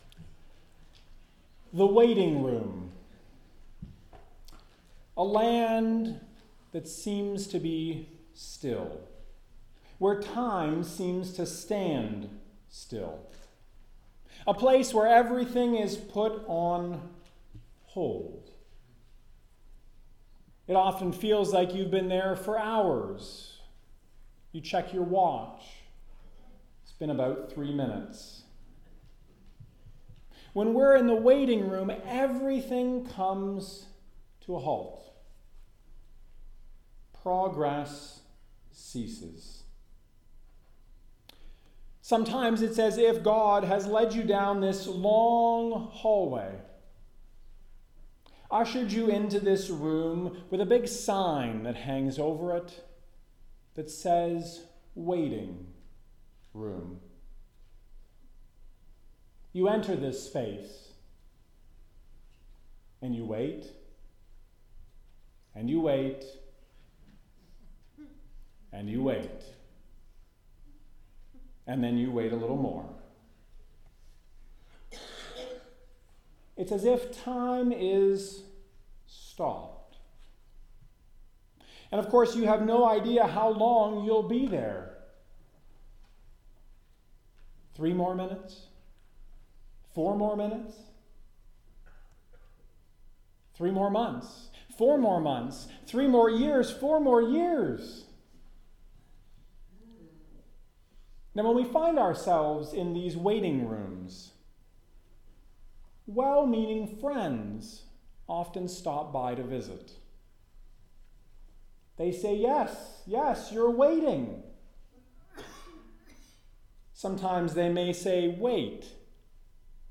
This sermon explores what it means to be patient in our faith journeys, to pause, and to make sure our steps are in line with God.